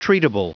Prononciation du mot treatable en anglais (fichier audio)
Prononciation du mot : treatable